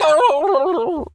turkey_ostrich_hurt_gobble_12.wav